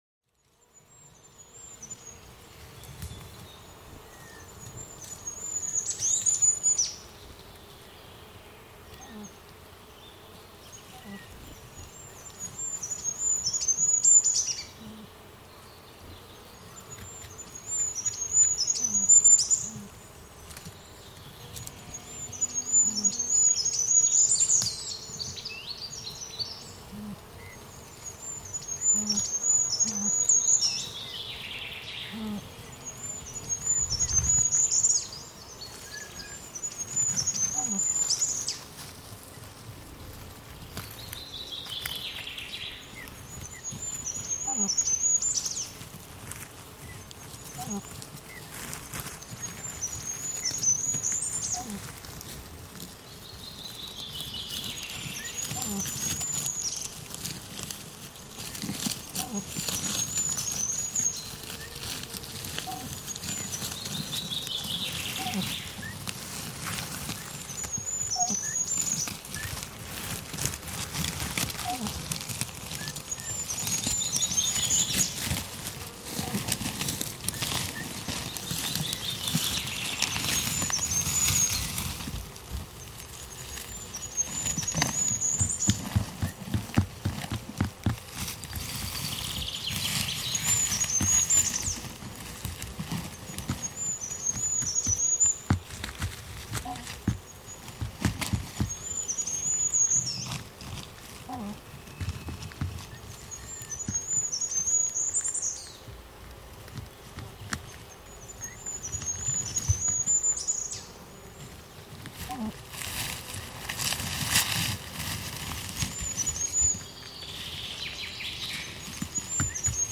Mäyrällä riittää ääniä
Mäyrä on hiljainen eläin, mutta sillä on monenlaisia ääniä. Ääninäytteellämme kuullaan sen ynähtelyä ja rapistelua, mutta se on samalla näyte mäyrän hiljaisuudesta.